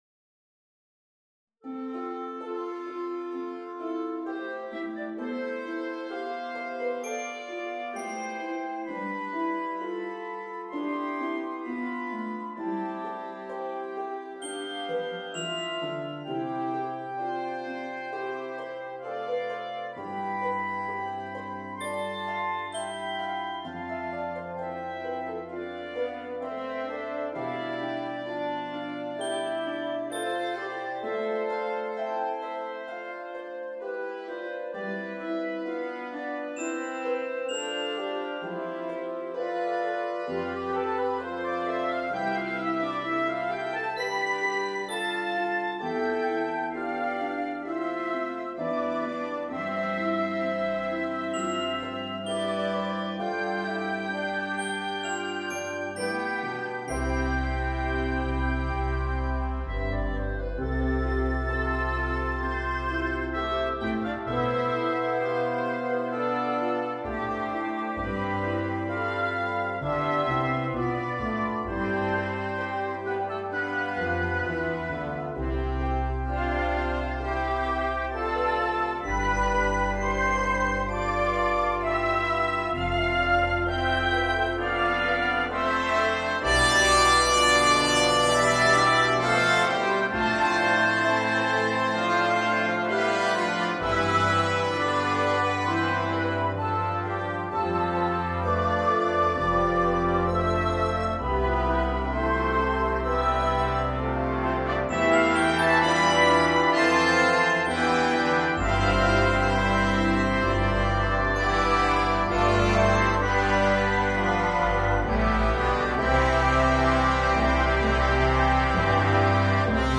The MP3 was recorded with NotePerformer 3.
Harp